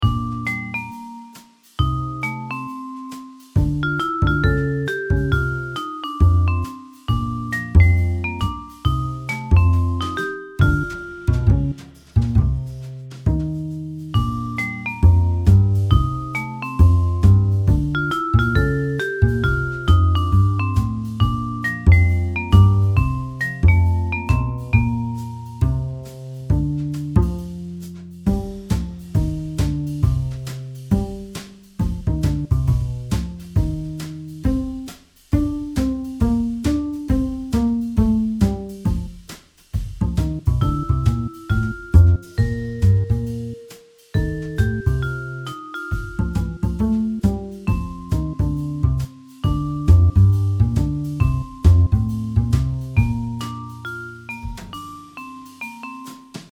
A little trio for Frog (vibes), Toad (bass), and Crab (drums). It ends abruptly because it's intended to be looped - because I made it for Looptober last month.